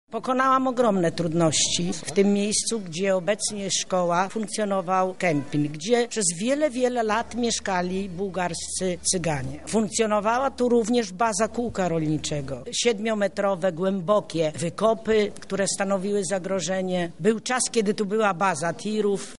Od 20 lat wszyscy mieszkańcy pytali czy nie zasługują na to by w tym miejscu powstała placówka potrzebna i konieczna – tłumaczy Jadwiga Mach radna Rady Miasta Lublin: